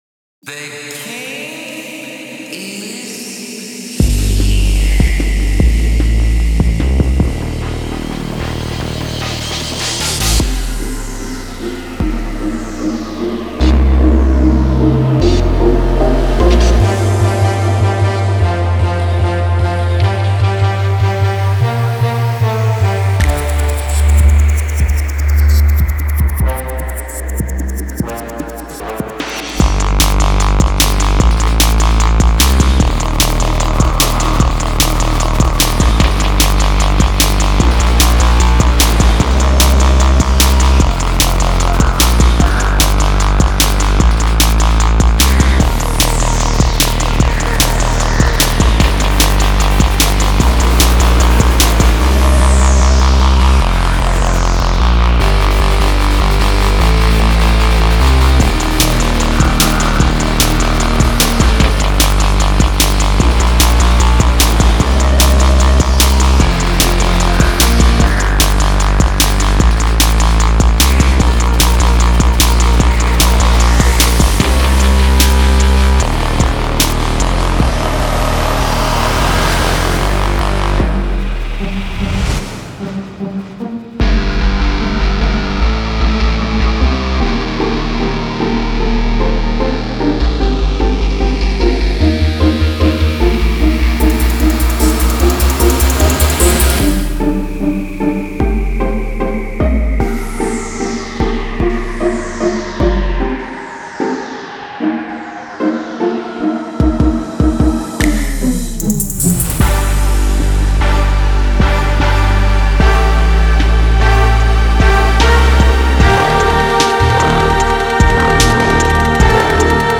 (8.13 MB, 24-01-13_fusionbreaks_1.mp3)
I like to make music on my puter
that part around 2:55 is very hype